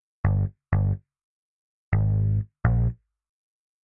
125 BPM循环播放 " 125 Bb科技屋贝斯
描述：125 bpm tech house bass loop
Tag: 低音回路 125-BPM 高科技室内